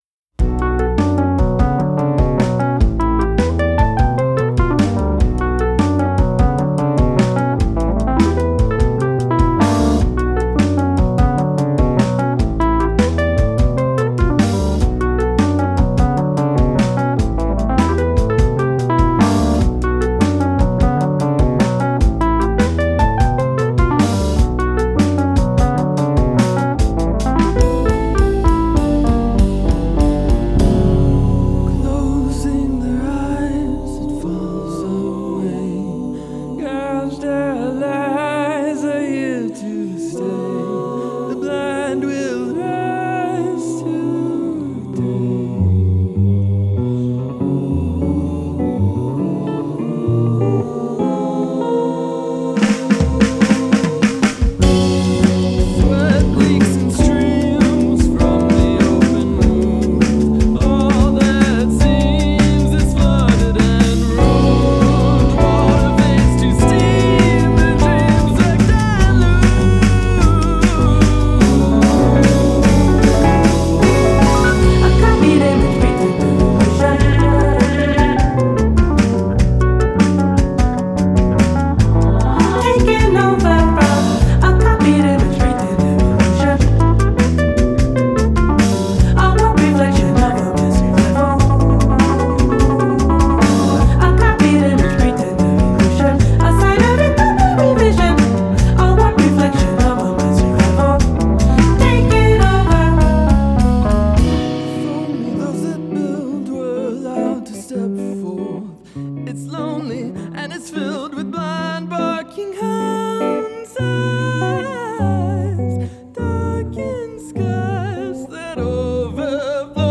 Genre: Fusion.